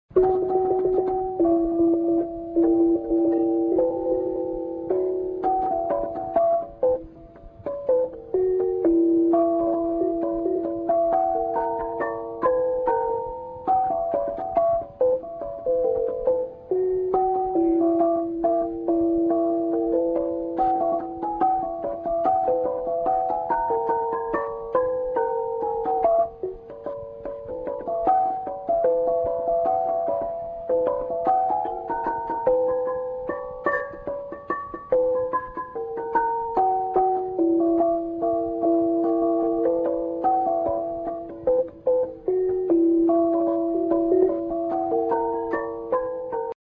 The kind which has a diameter of from 30-45 cm., when beaten, gives the sound mong, and this sound is used for the name—khawng mong or simply mong.
gongmon.mp3